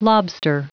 Prononciation du mot lobster en anglais (fichier audio)
Prononciation du mot : lobster